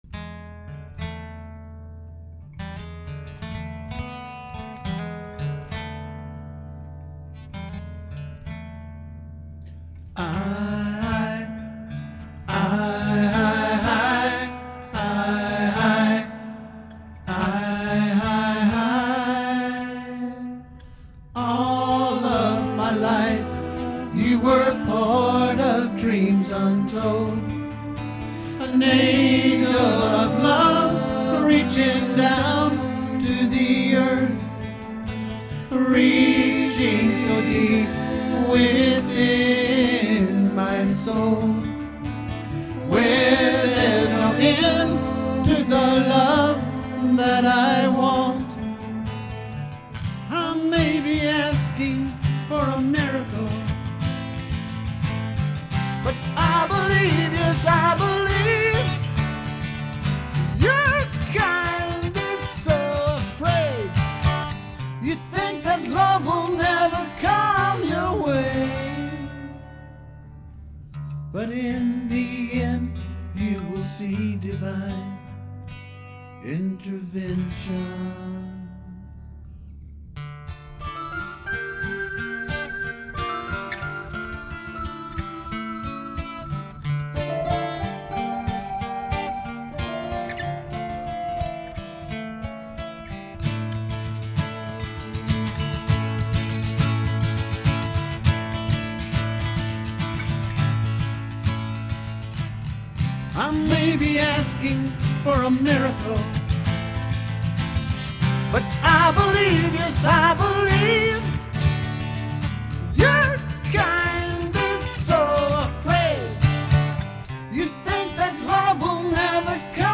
ORIGINAL ACOUSTIC SONGS
BASS